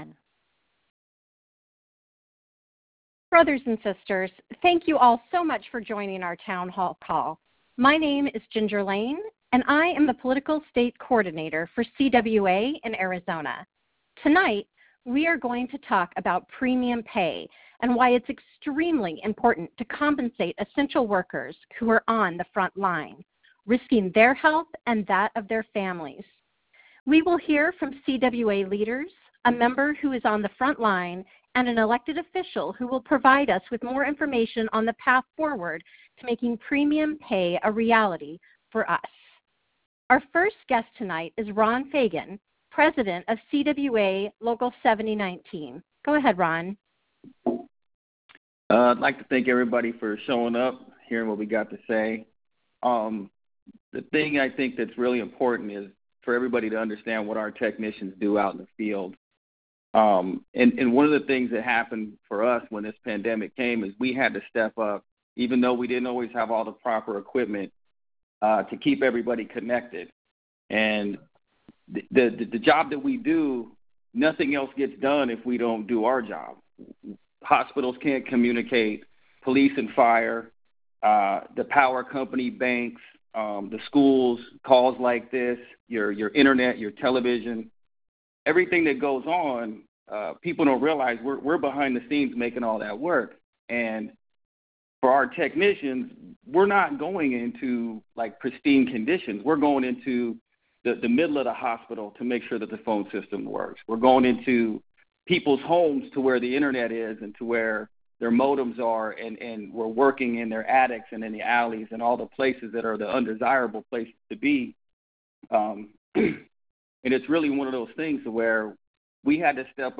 Town Hall Calls to Discuss Essential Workers and the Hero’s act in Arizona and Colorado